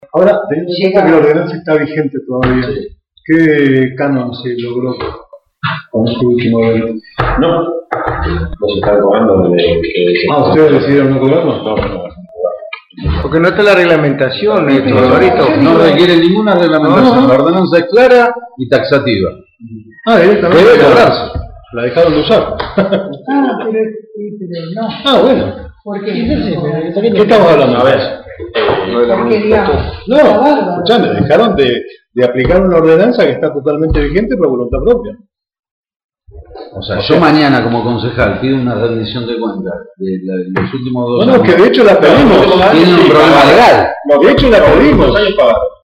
En el mismo programa, minutos más tarde reprodujeron parte del audio de la reunión de en la que Apaolaza, ante la pregunta de cuánto se había cobrado de canon en la última competencia, declara suscintamente: «no se está cobrando».
Audio Comisión de Turismo – Alejandro Apolaza